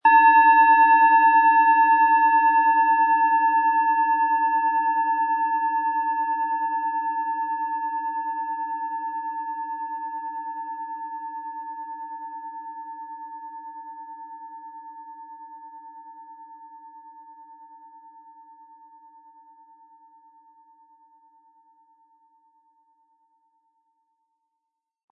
Planetenschale® Leidenschaftlich sein & Fröhlich fühlen mit Eros, Ø 12,1 cm, 180-260 Gramm inkl. Klöppel
HerstellungIn Handarbeit getrieben
MaterialBronze